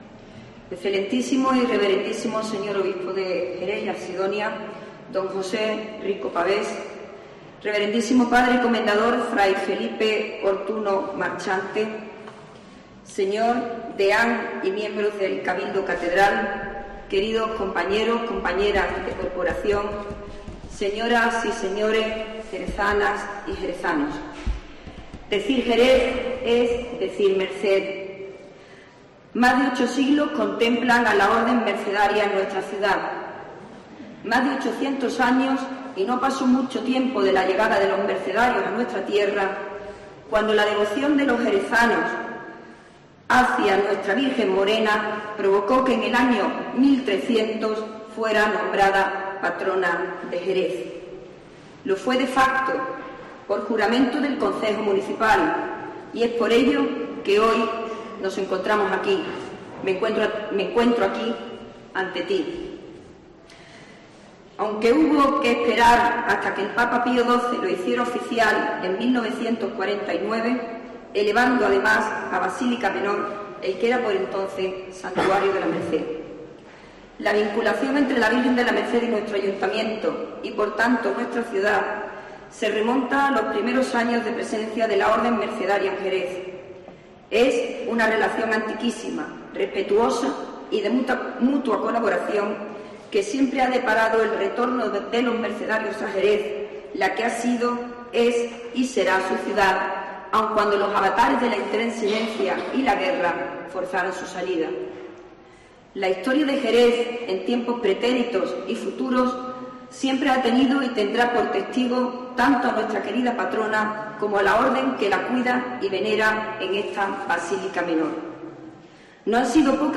Un sonoro "viva la Virgen de la Merced, viva Jerez", rubricado por el fuerte aplauso de las personas que llenaban la Basílica de Nuestra Señora de la Merced para participar en la celebración de la Pontifical del día de la Patrona, ha puesto colofón este 24 de septiembre a la tradicional renovación del Voto de la Ciudad de Jerez de la Frontera a su Patrona.